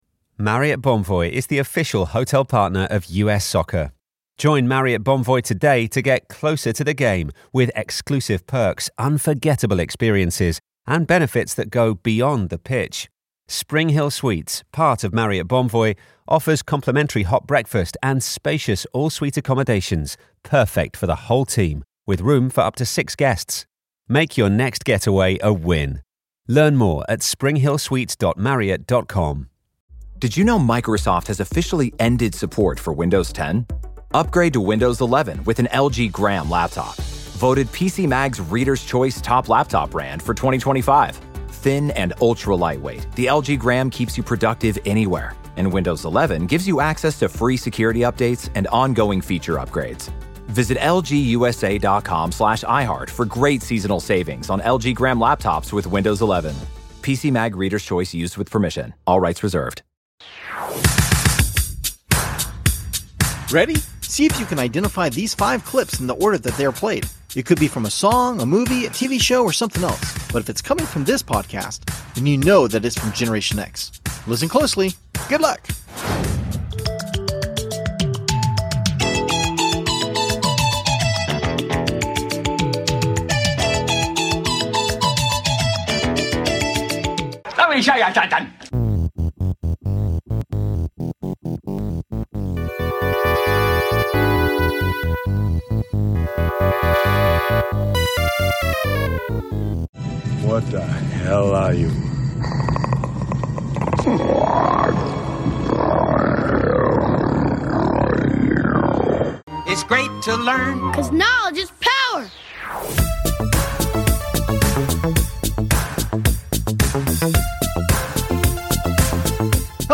We welcome two first time guests who are both professional entertainers, to bring plenty of laughs as we play games and reminisce about our pop culture during Generation X. We recorded this a LONG time ago, and my apologies to our guests for making them wait so long to get it out to everyone...